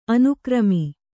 ♪ anukrami